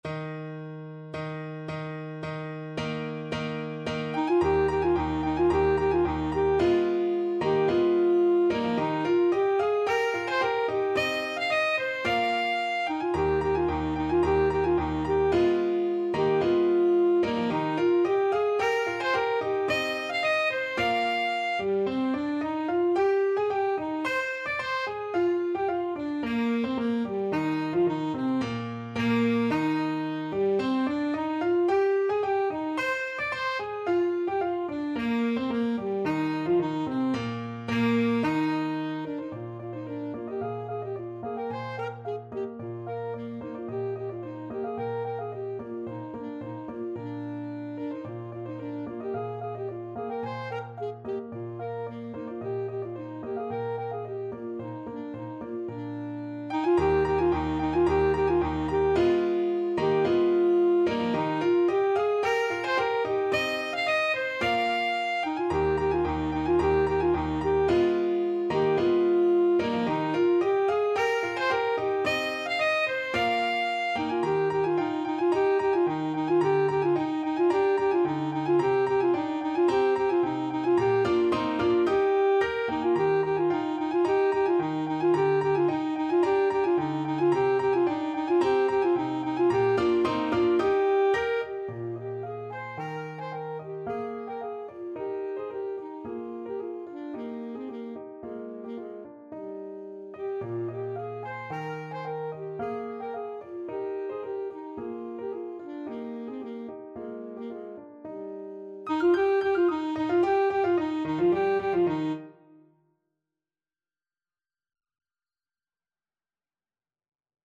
Alto Saxophone version
2/2 (View more 2/2 Music)
= 110 Allegro di molto (View more music marked Allegro)
Classical (View more Classical Saxophone Music)